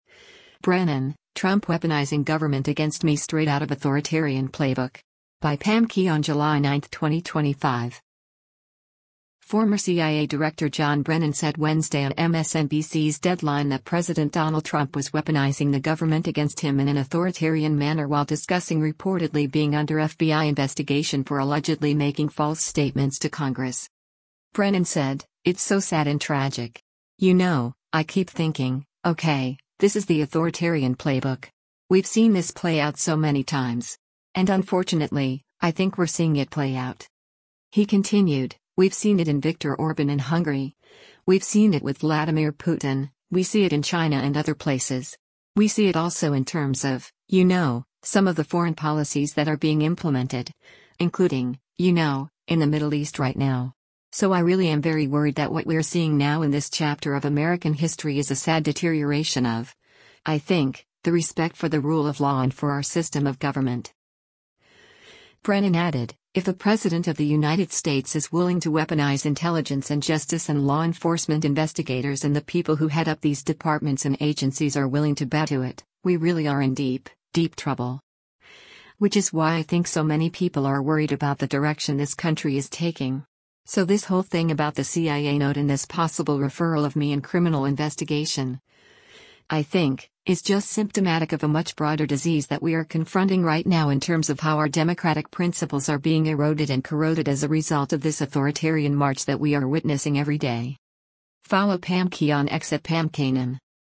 Former CIA Director John Brennan said Wednesday on MSNBC’s “Deadline” that President Donald Trump was weaponizing the government against him in an authoritarian manner while discussing reportedly being under FBI investigation for allegedly making false statements to Congress.